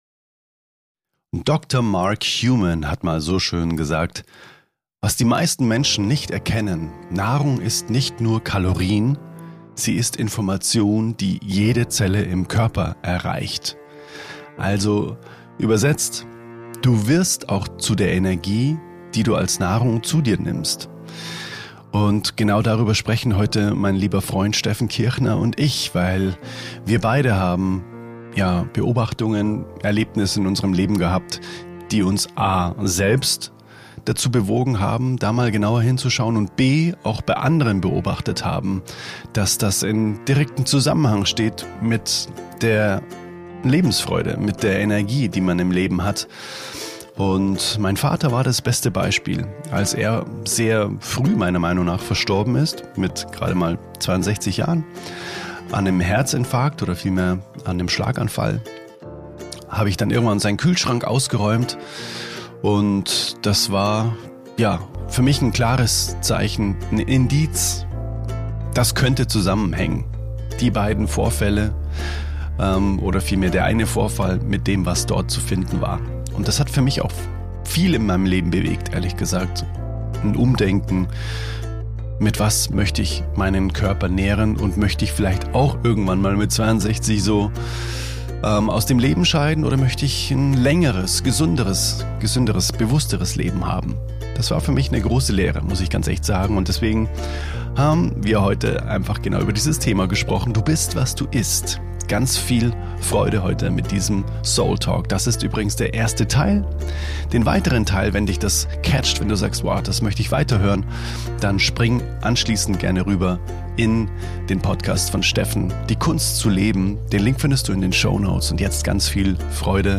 Wir sprechen über bewusste Ernährung, energetische Schwingung von Lebensmitteln – und wie du spürst, ob dich deine Ernährung stärkt oder schwächt. Ein Gespräch, das dich auf Zellebene berühren kann.